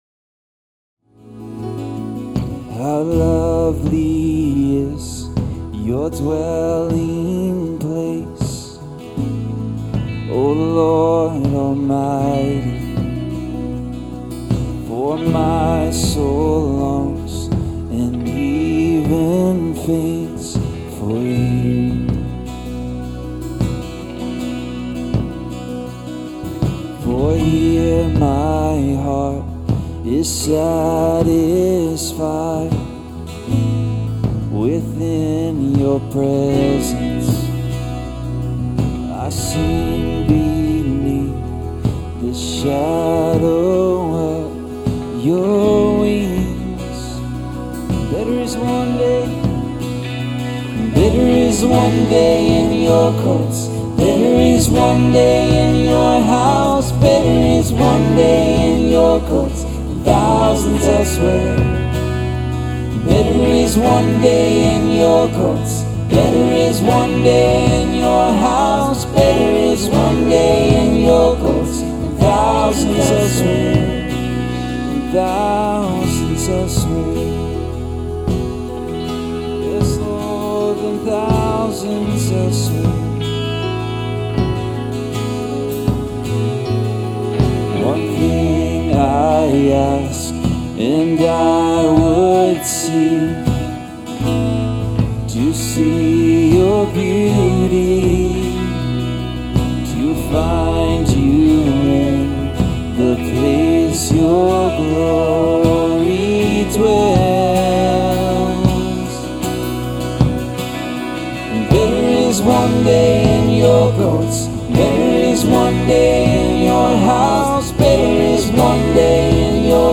Enjoy a worship set that features songs from our series on the book of Psalms.